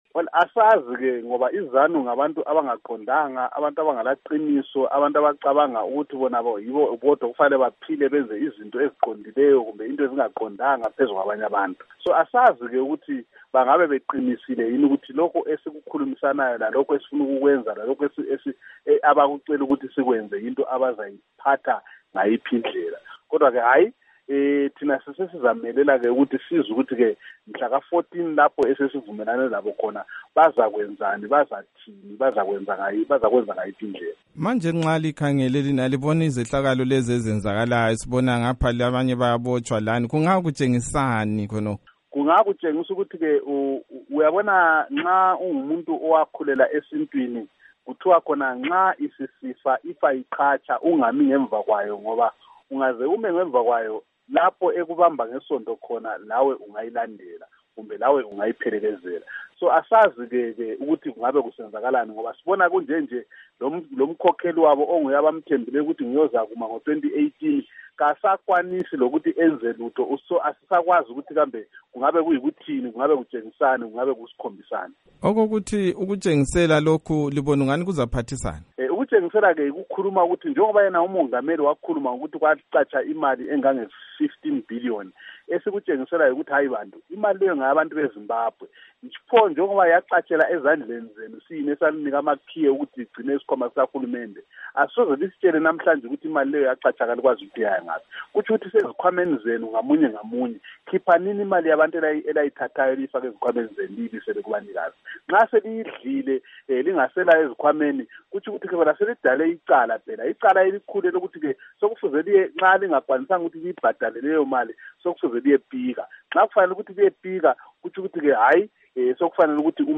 Ingxoxo loMnu. Abednigo Bhebhe